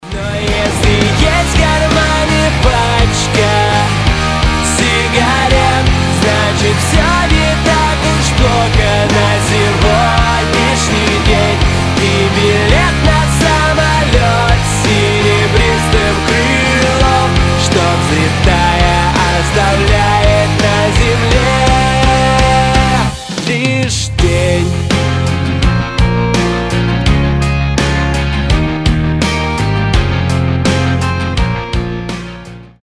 • Качество: 320, Stereo
громкие
Alternative Rock
кавер
Альтернативный рок